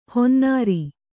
♪ honnāri